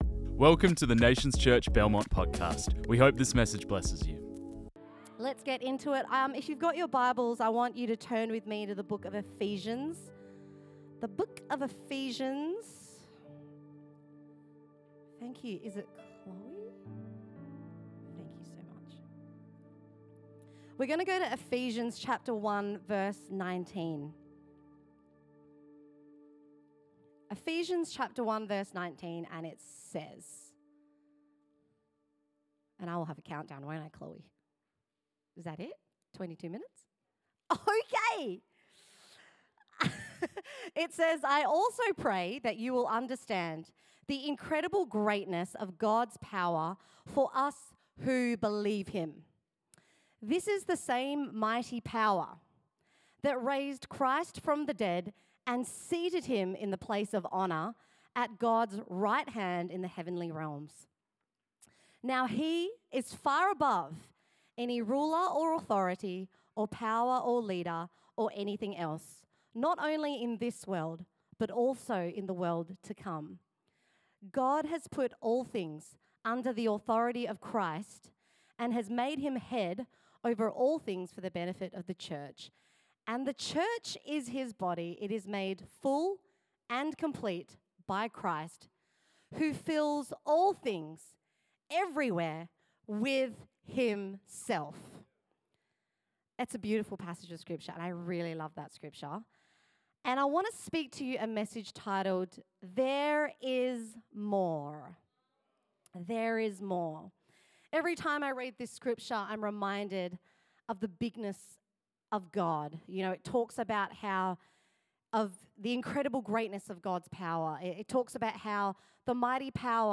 This message was preached on 29 June 2025.